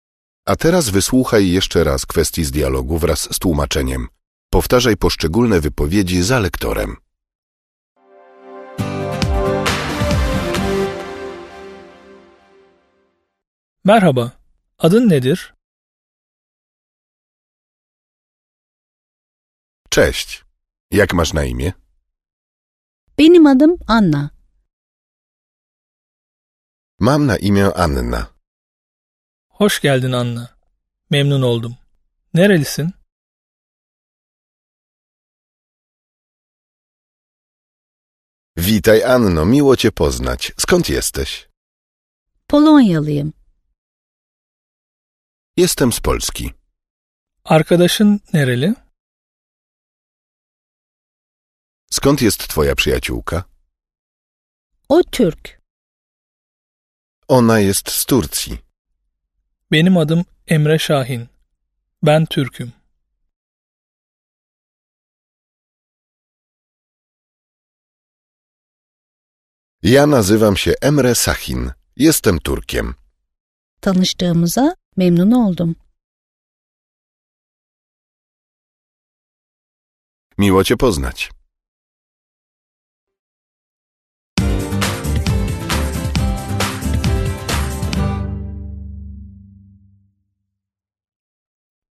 Wysokiej jakości nagrania (144 min.) pozwalają osłuchać się z językiem, poznać jego melodię oraz prawidłową wymowę. Wszystkie słówka, zwroty oraz dialogi wraz z tłumaczeniami zostały udźwiękowione przez profesjonalnych lektorów.